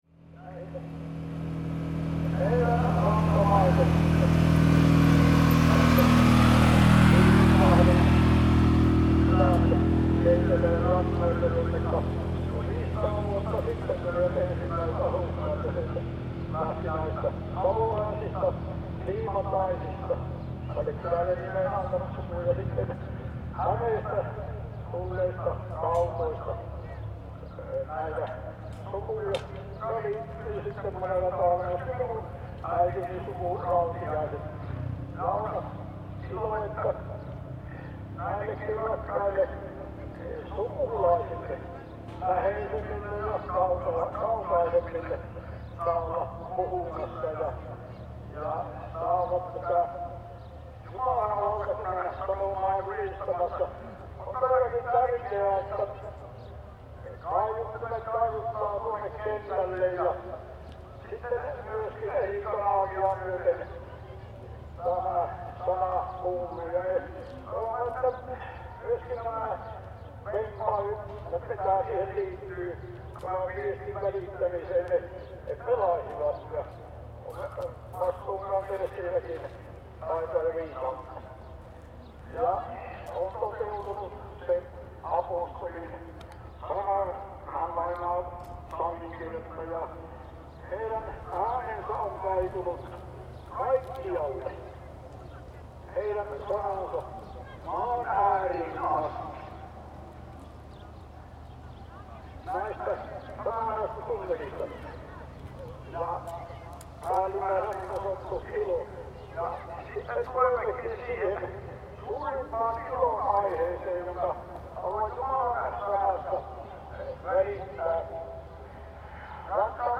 Summer Services I: Announcements Near the Festival Area
• Soundscape
• announcement